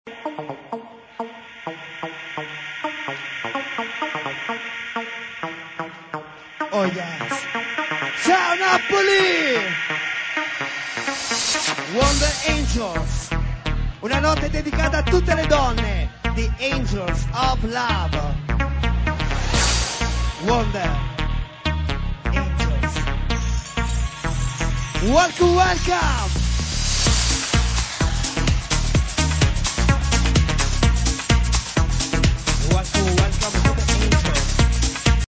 proggy tune